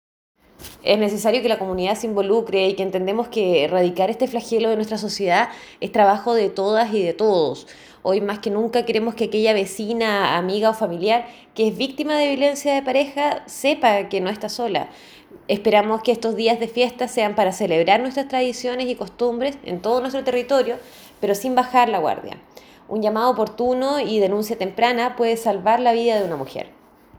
CUÑA-2-SEREMI-MMYEG-LOS-RIOS-KARLA-GUBERNATIS.mp3